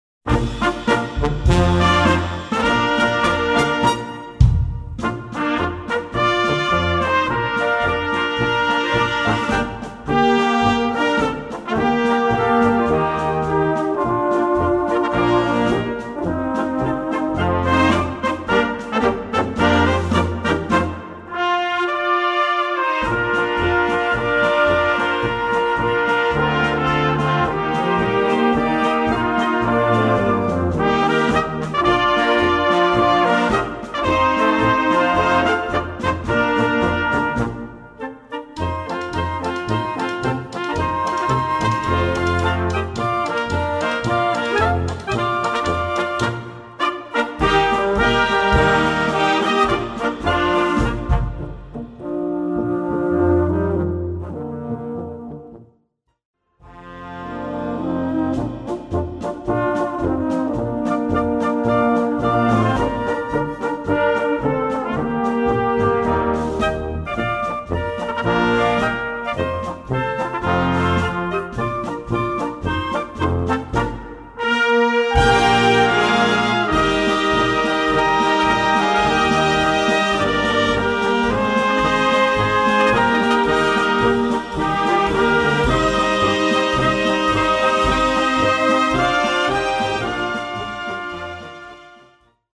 Besetzung: Kleine Blasmusik-Besetzung